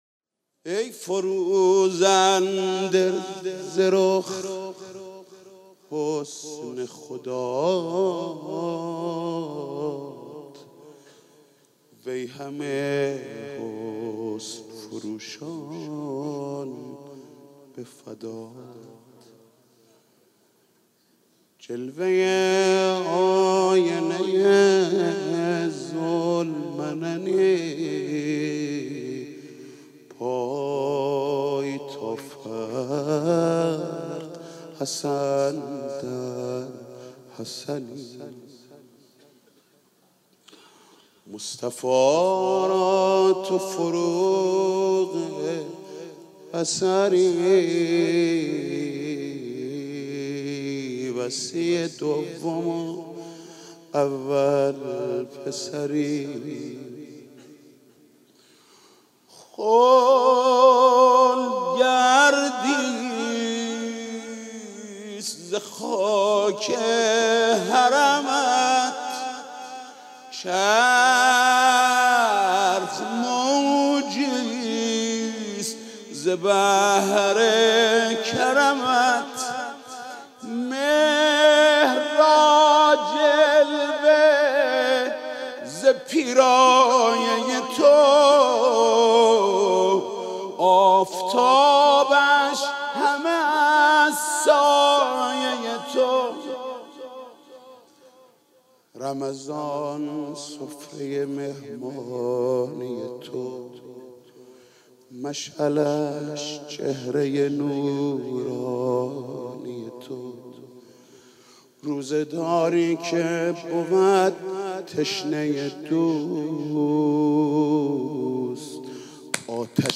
مدح: ای فروزنده ز رخ حسن خدا